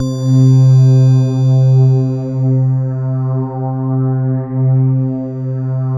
PAD FLYIN0ER.wav